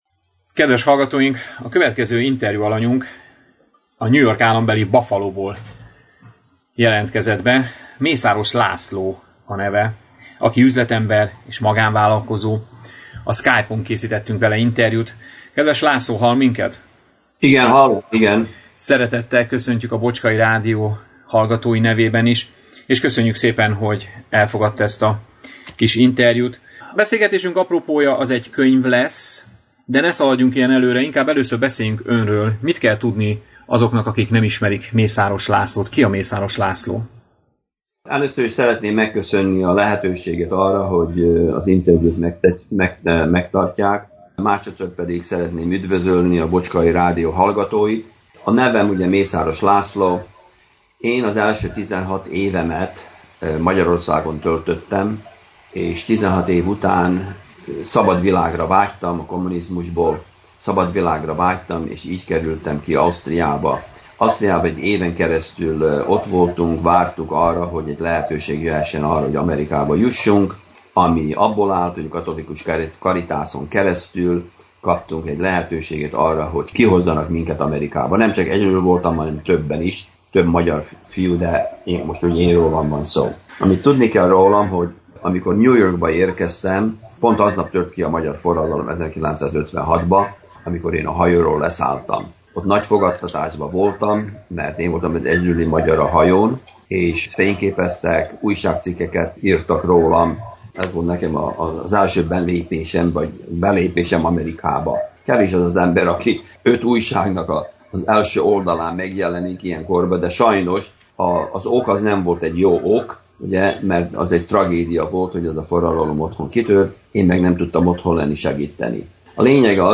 Soha ne add fel - Interjú